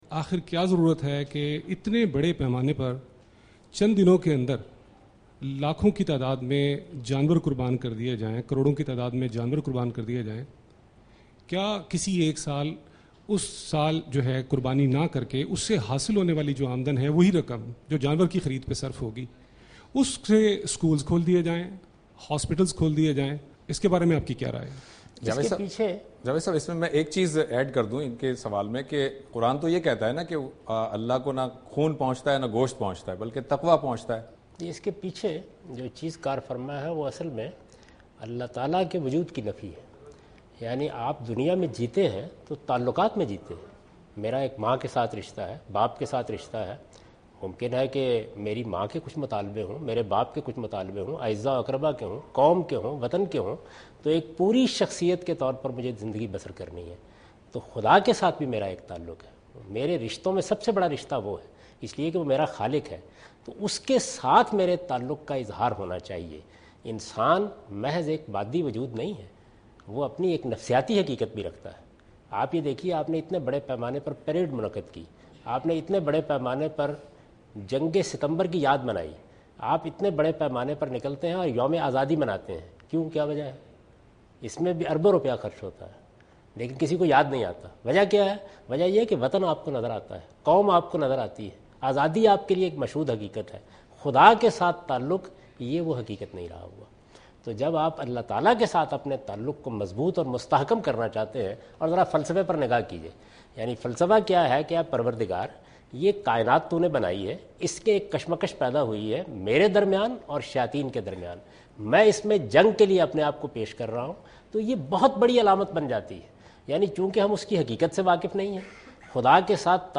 In this video Javed Ahmad Ghamidi answer the question about "skipping animal sacrifice for social causes " in program Ilm-o-Hikmat on Dunya News.
دنیا نیوز کے پروگرام علم و حکمت میں جاوید احمد غامدی "مفاد عامہ کے لیے قومی سطح پر قربانی نہ کرنا" سے متعلق ایک سوال کا جواب دے رہے ہیں